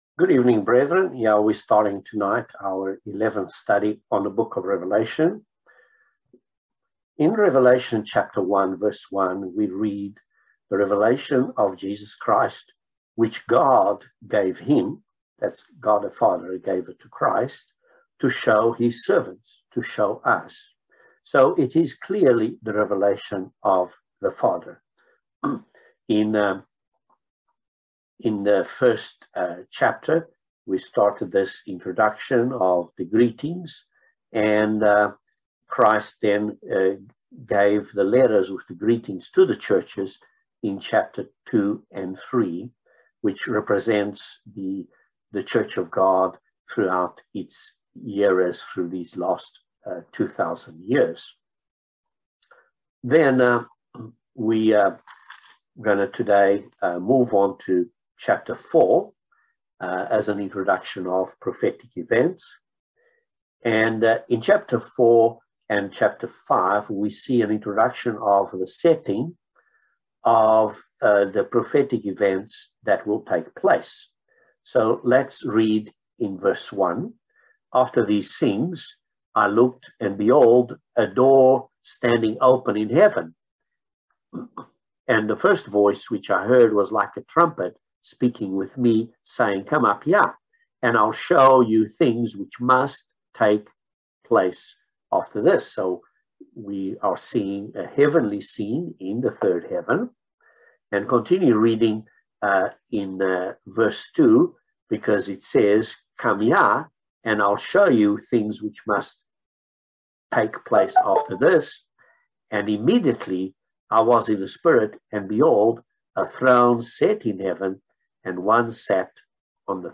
Bible Study no 11 of Revelation